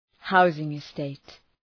Προφορά